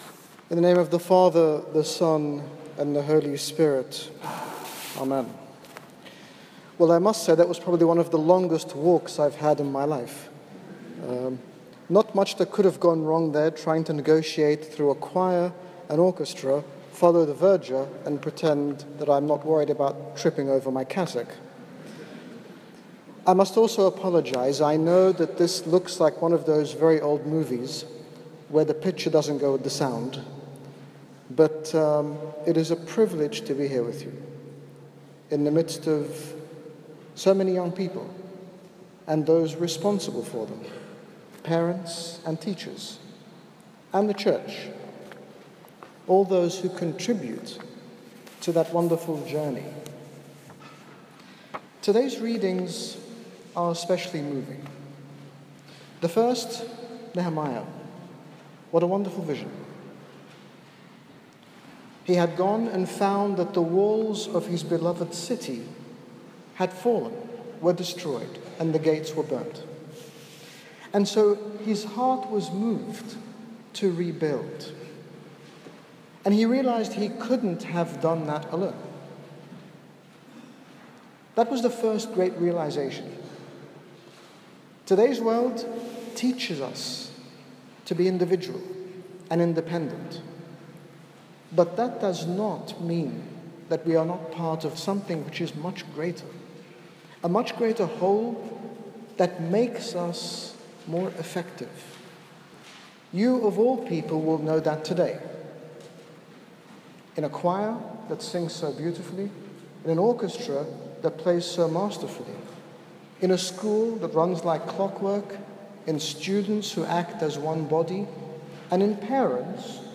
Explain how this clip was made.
Bath Abbey St Edwards Fouders Day 2016.mp3